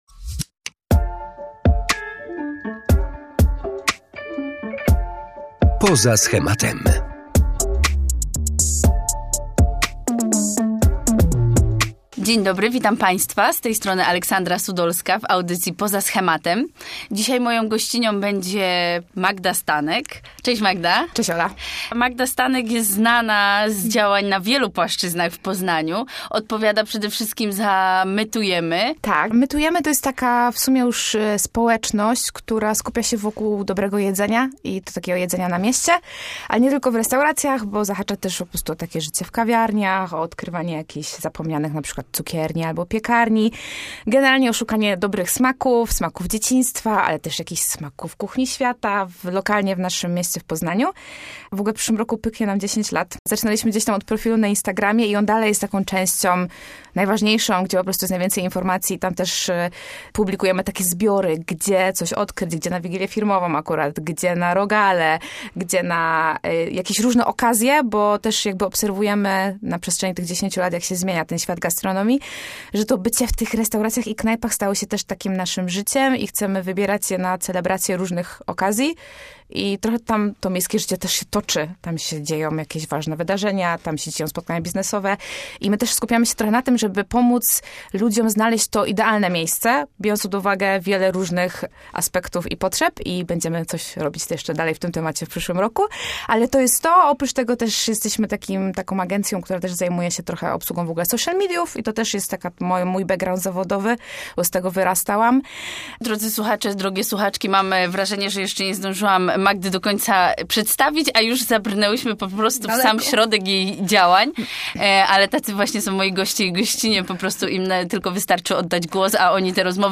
ROZMOWA O WSPÓŁCZESNYM RODZICIELSTWIE